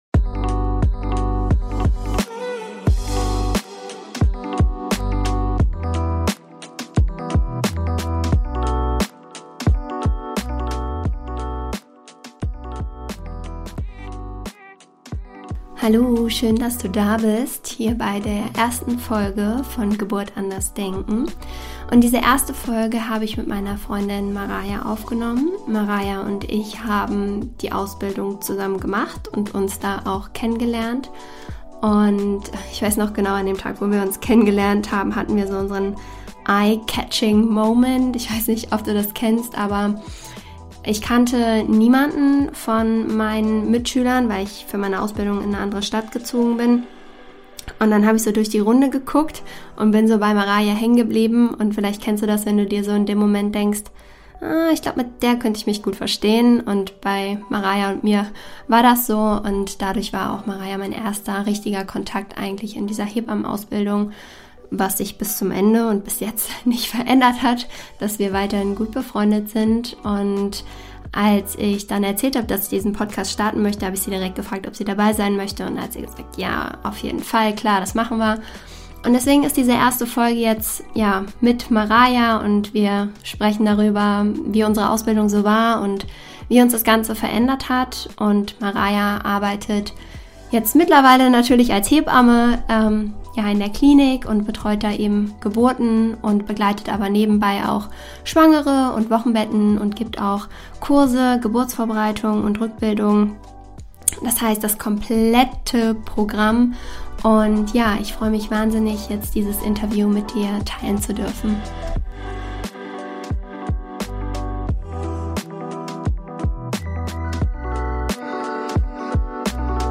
Es ist ein ehrliches Gespräch über die Ausbildungszeit, über das Hebamme werden und Hebamme sein, über Klinikalltag, Klinikstrukturen und -hierarchien.